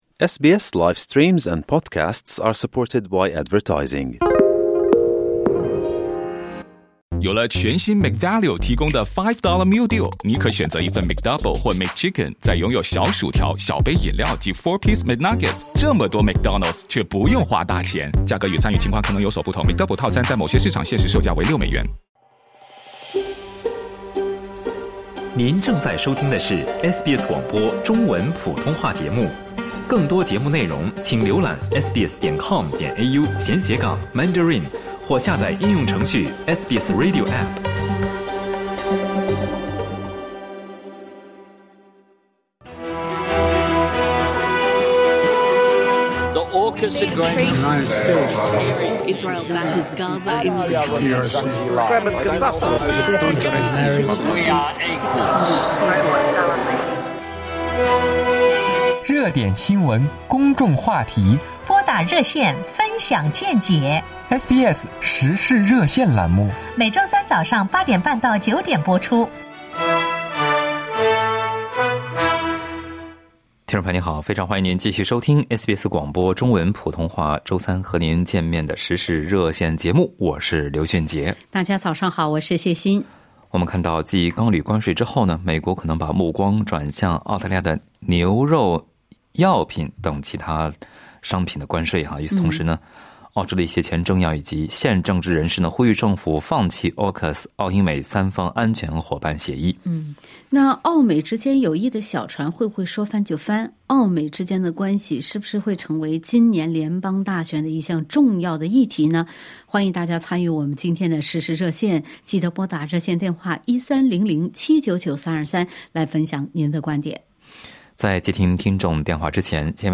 SBS普通话《时事热线》节目听友分享了各自的看法。